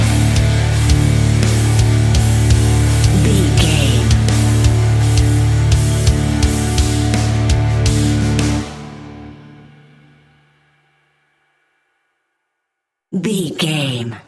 Epic / Action
Fast paced
Aeolian/Minor
hard rock
horror rock
instrumentals
Heavy Metal Guitars
Metal Drums
Heavy Bass Guitars